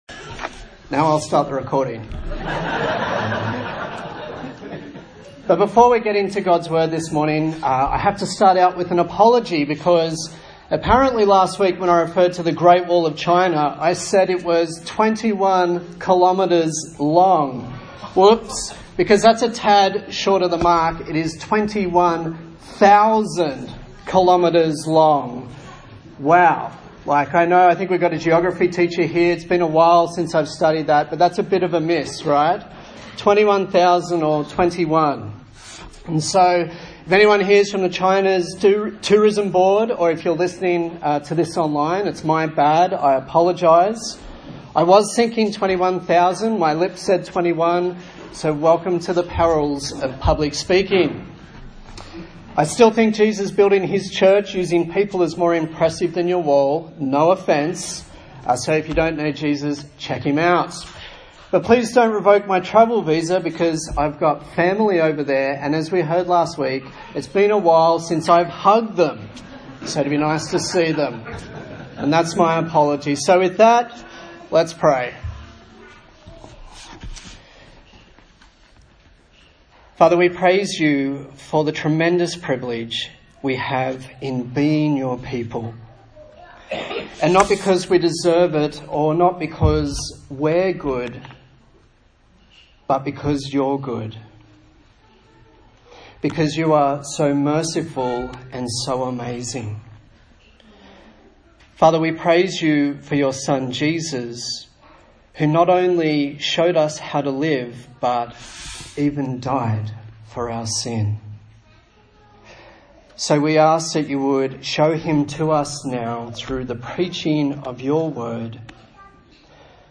A sermon in the series on the book of 1 Peter
Service Type: Sunday Morning